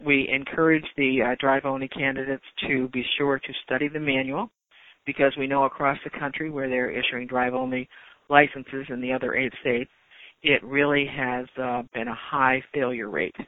CONNECTICUT’S DMV COMMISSIONER MELODY CURREY SAYS THE STATE HAS WORKED CLOSELY WITH IMMIGRANT ADVOCATE GROUPS TO TRAIN TRAINERS TO SO THEY CAN COACH IMMIGRANTS HOW TO TAKE THE TEST. NATIONALLY, IMMIGRANTS HAVE HAD A HIGH FAILURE RATE, SHE SAYS.